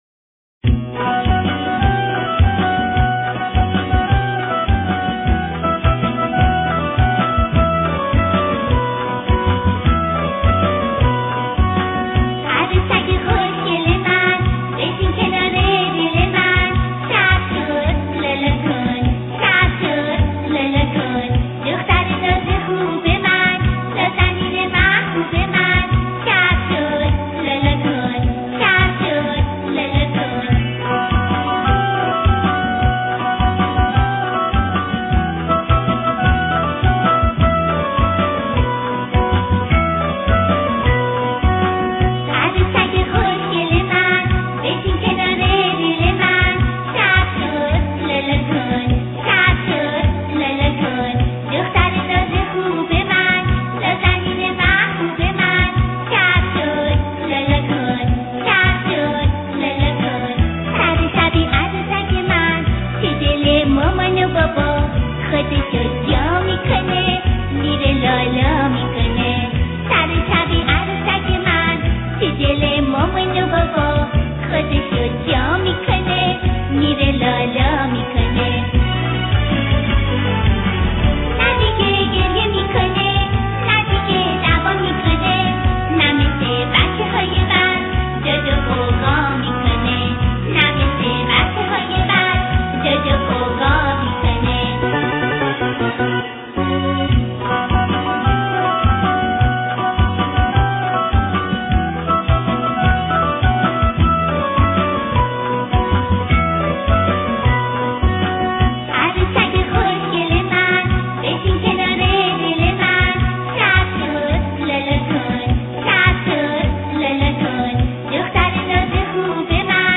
قصه صوتی